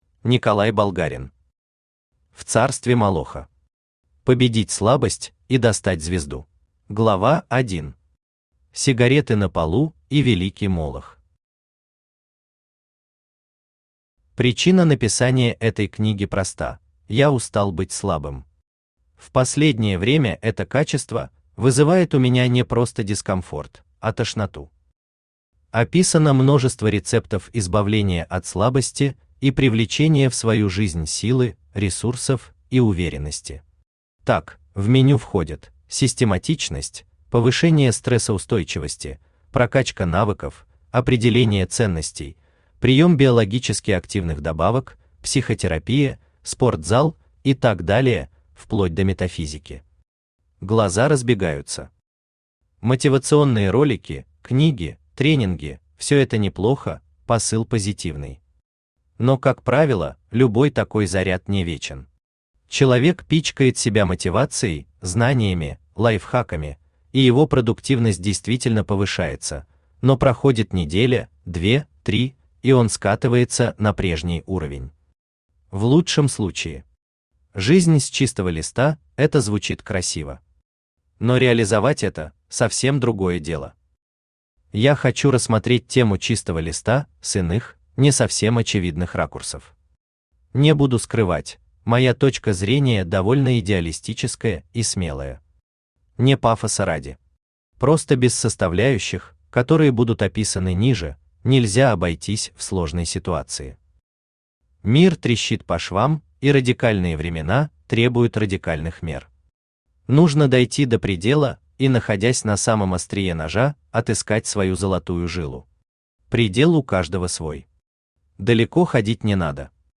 Победить слабость и достать звезду Автор Николай Болгарин Читает аудиокнигу Авточтец ЛитРес.